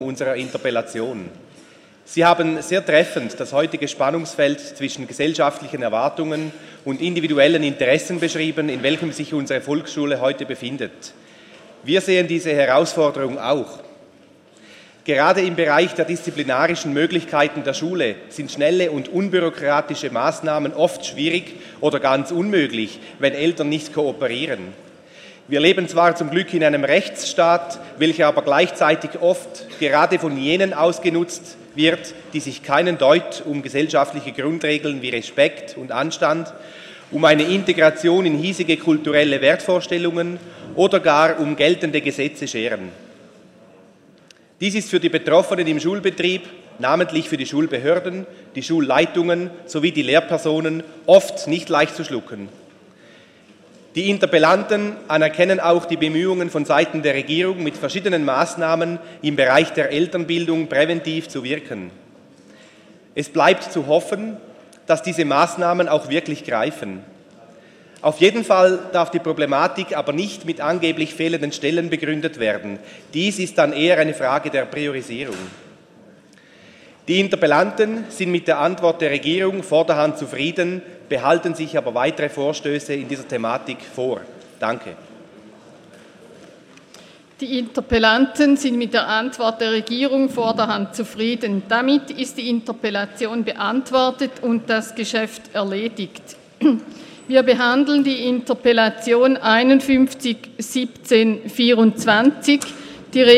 20.9.2017Wortmeldung
Session des Kantonsrates vom 18. bis 20. September 2017